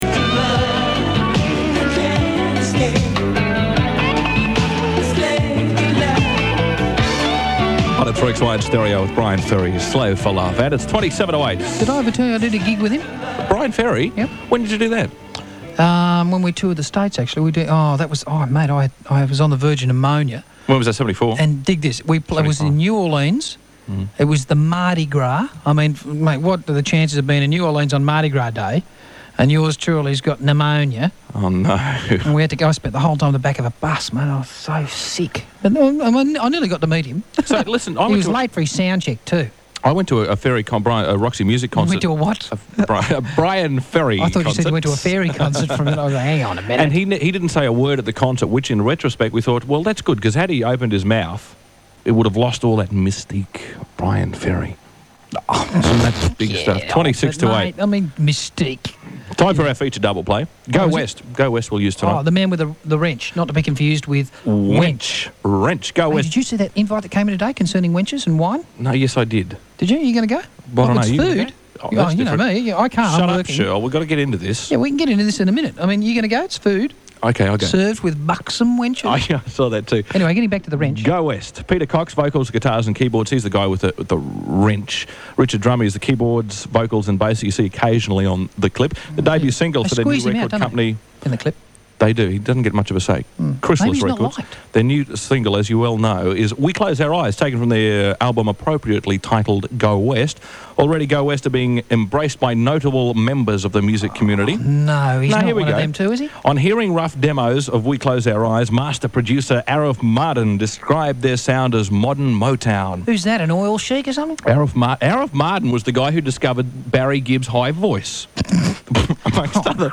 Most of this is sourced from various analogue sources including acetate discs real to real tapes and the humble cassette.